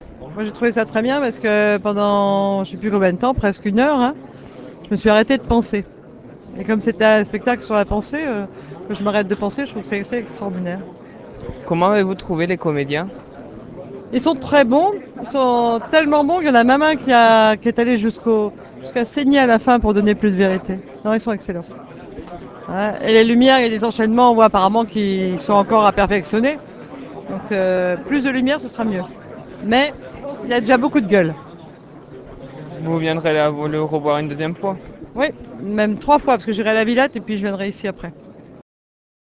Impressions à vif de Marylise Lebranchu : Ecoutez